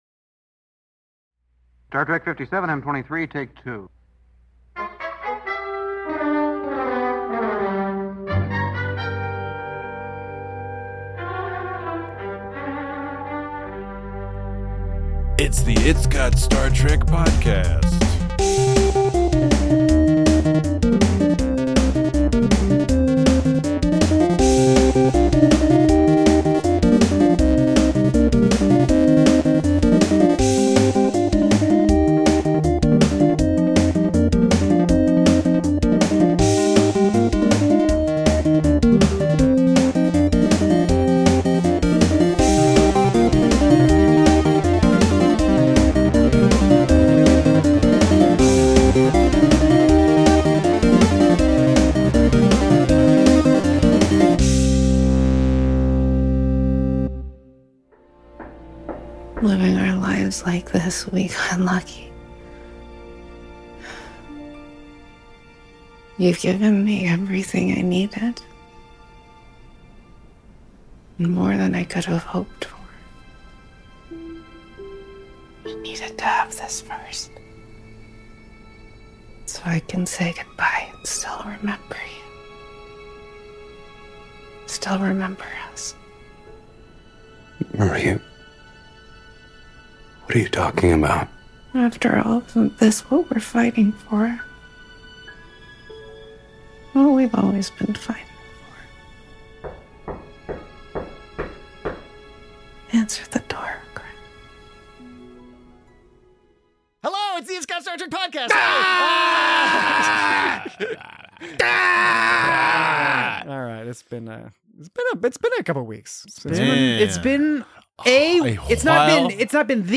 Captain Pike sees the inner light in Captain Batel's eyes. Join your frustrated hosts as they discuss the good, the bad, and the just plain confusing of this strangely constructed season finale.